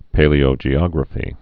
(pālē-ō-bīō-jē-ŏgrə-fē)